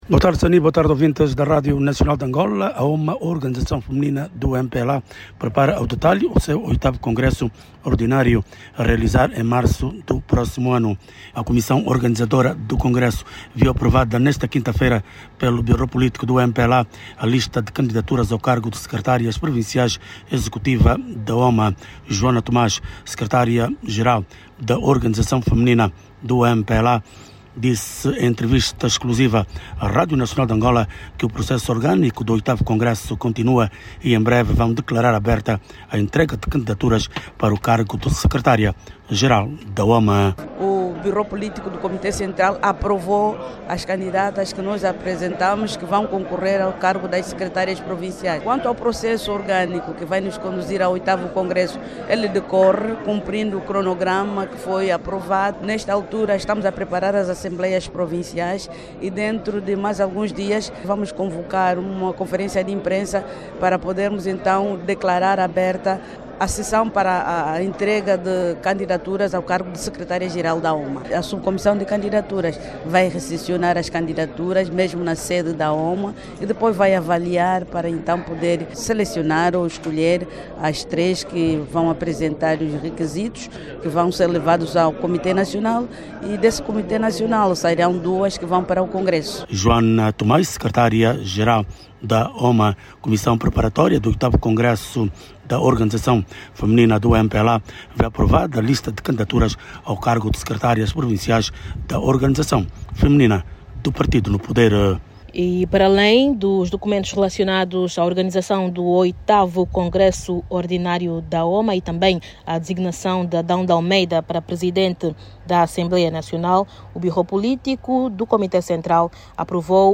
A decisão foi hoje tomada durante a primeira reunião extraordinária do bureau politico do comité central do MPLA, orientada pelo presidente do partido. Ainda durante a reunião foram aprovadas as candidaturas ao cargo de secretárias executivas provinciais da OMA. Saiba mais dados no áudio abaixo com a repórter